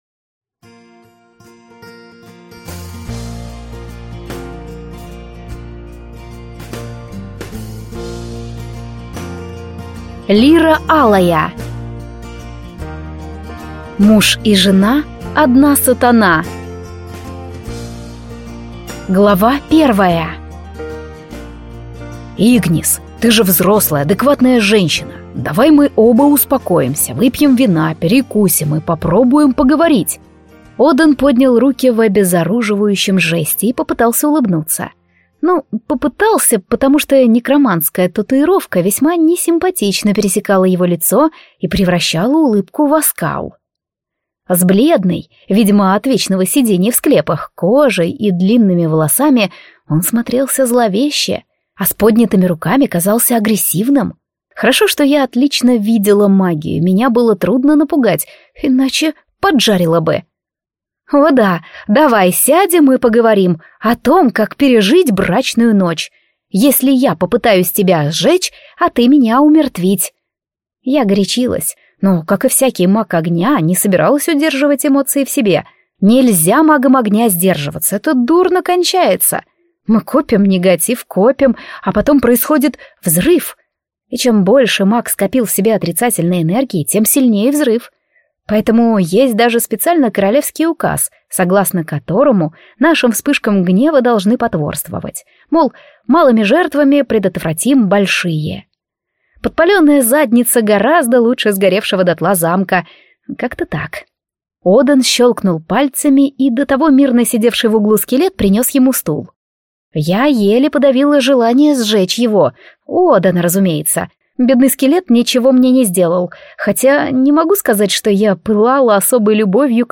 Аудиокнига Муж и жена – одна сатана | Библиотека аудиокниг